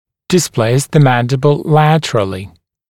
[dɪs’pleɪs ðə ‘mændɪbl ‘læt(ə)r(ə)lɪ][дис’плэйс зэ ‘мэндибл ‘лэт(э)р(э)ли]смещать нижнюю в сторону(-ы), вбок